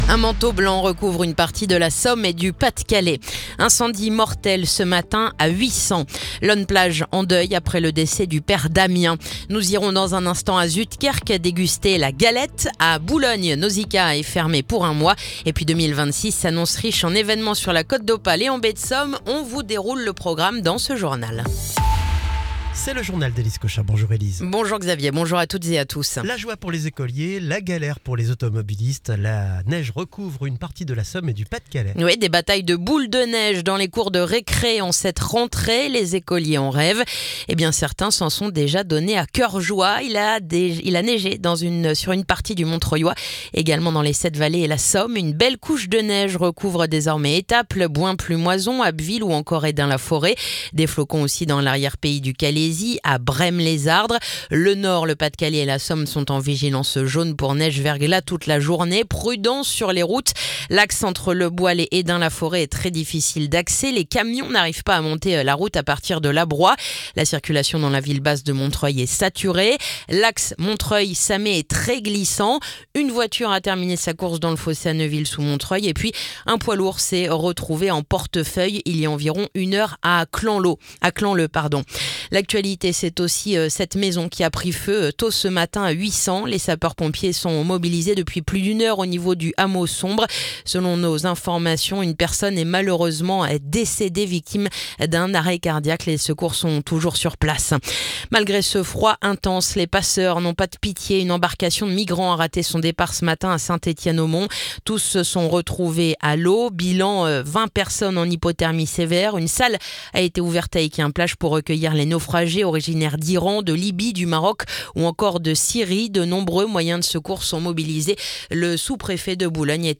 Le journal du lundi 5 janvier